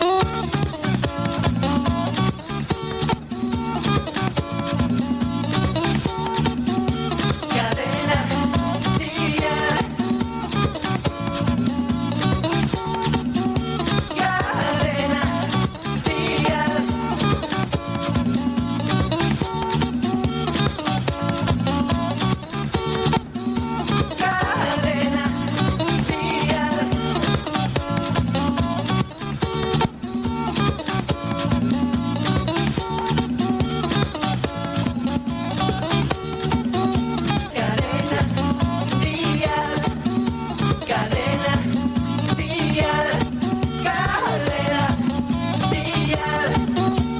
Indicatiu cantat sense fi